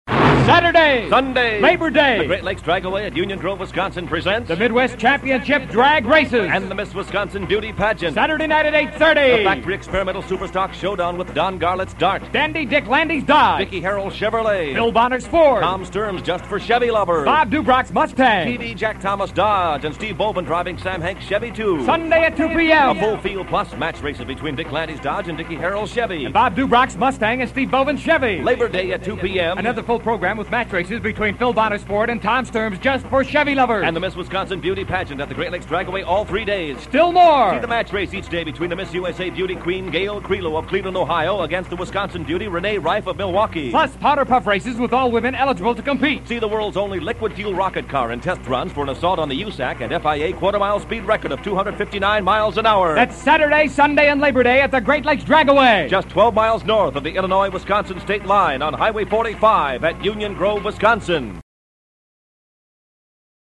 Drag Strip Radio Spots